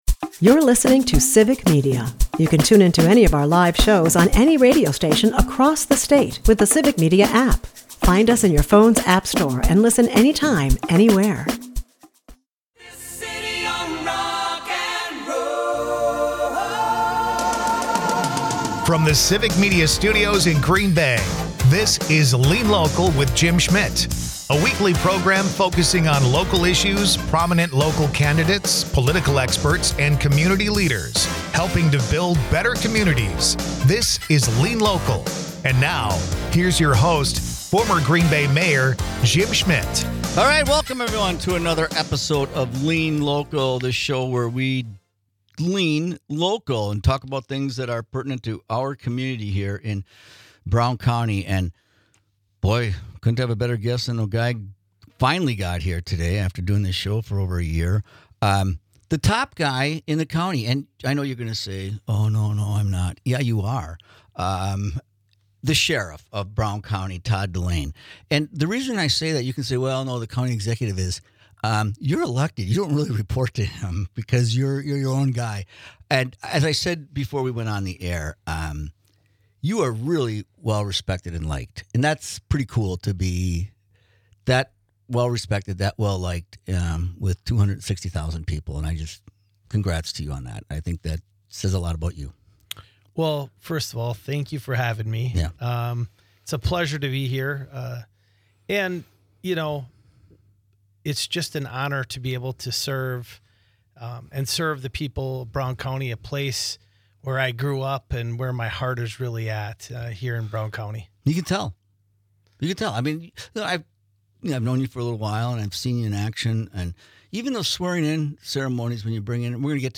Former Green Bay Mayor Jim Schmitt, is joined by current Brown County Sheriff, Todd Delain. Jim and Todd talk about the current prison system, homelessness, and Brown County.